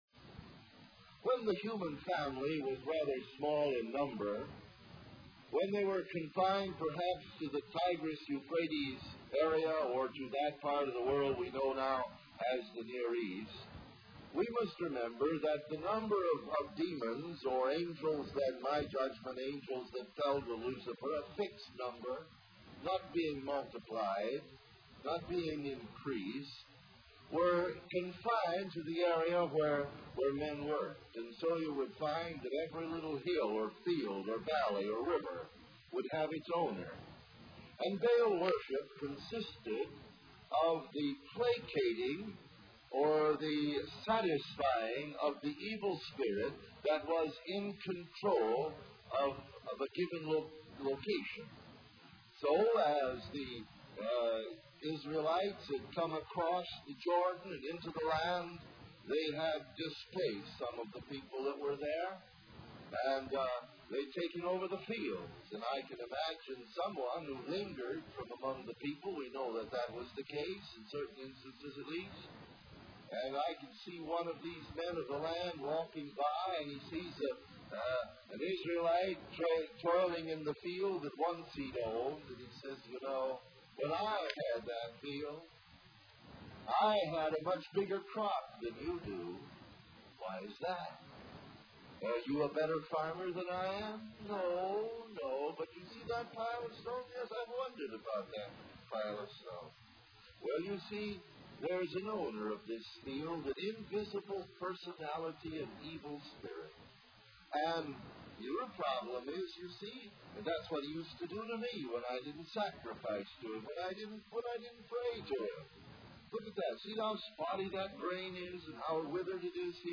In this sermon, the speaker shares a personal story of how he realized he was lost and disobedient to his parents.